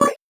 Index of /musicradar/8-bit-bonanza-samples/VocoBit Hits
CS_VocoBitC_Hit-02.wav